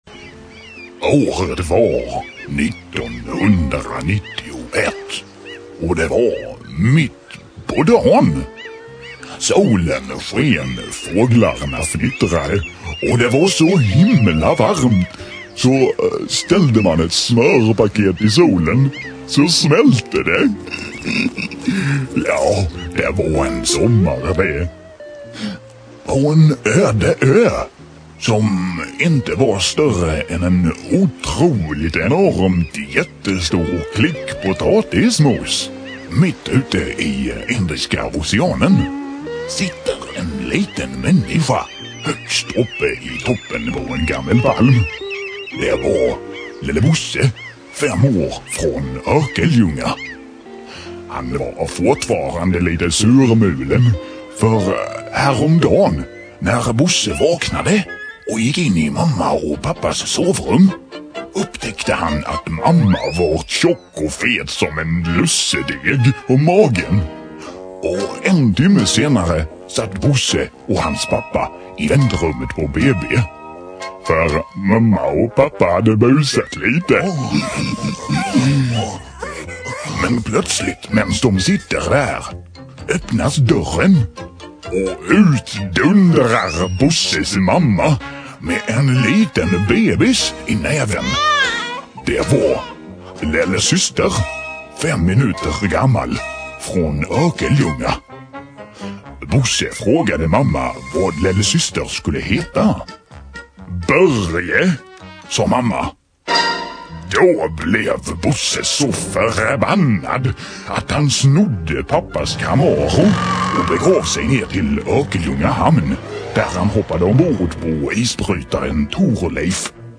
"Lelle Bosse" berättelser
Lo-fi filerna är samma som originalet men i mono och lite sämre ljudkvalisort - snabbare att ladda hem.